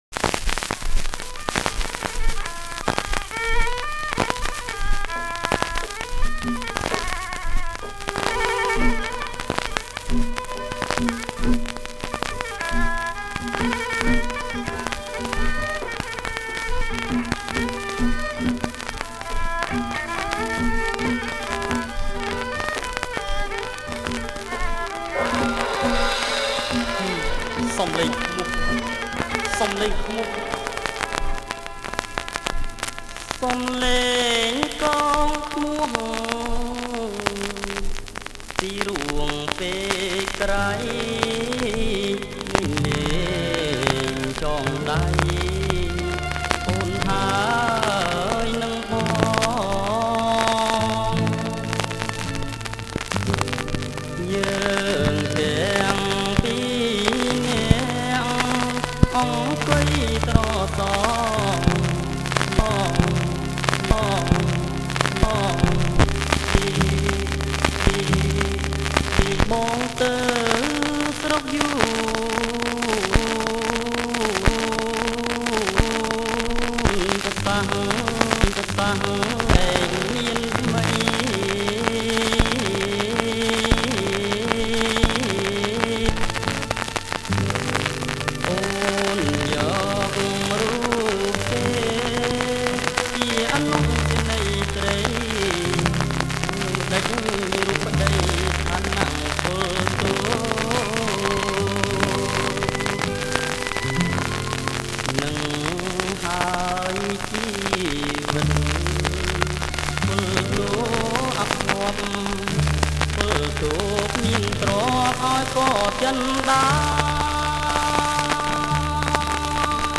• ប្រគំជាចង្វាក់ Bolero Sentimental